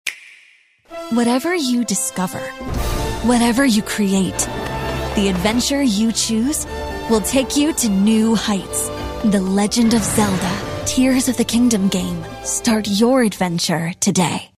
Female
English (North American)
Teenager (13-17), Yng Adult (18-29)
An authentic child/teen or GenZ sound , that's real, playful, compassionate.
Promo Legend Of Zelda Vgame Ya
All our voice actors have professional broadcast quality recording studios.